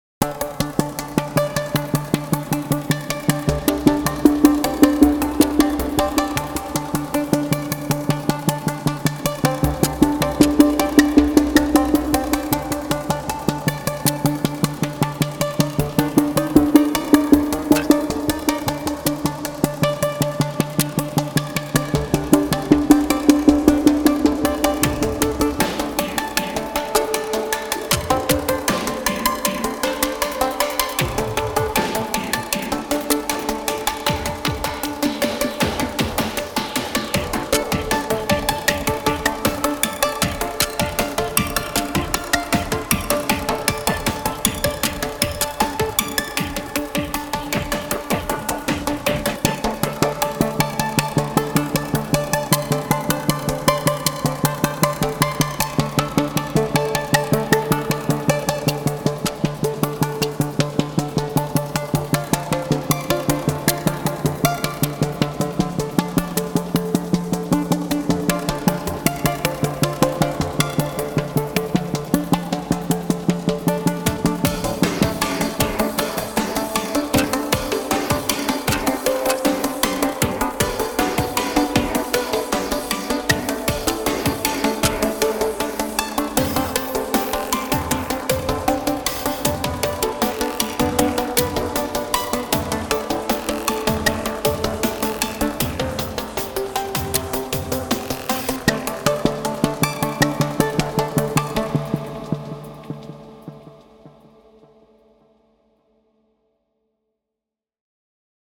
Synth pulsy track for puzzle.